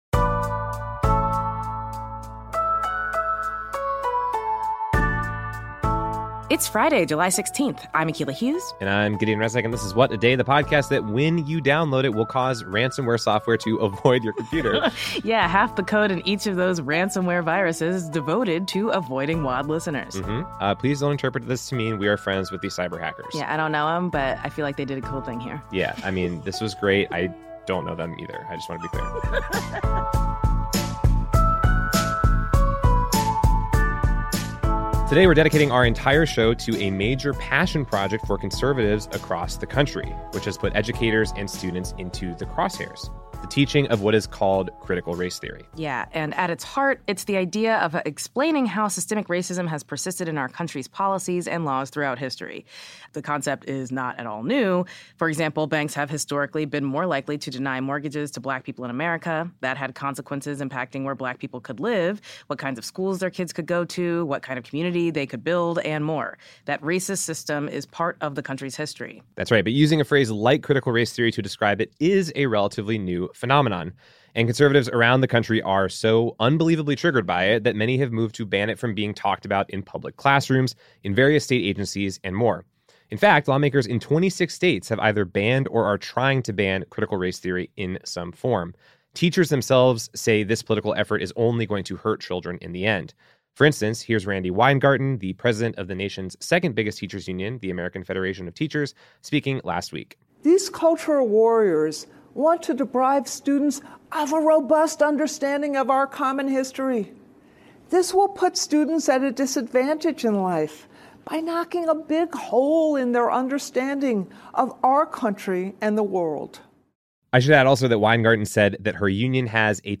We spoke with two educators about the impact that the country-wide politicization of CRT is having on them and on their students.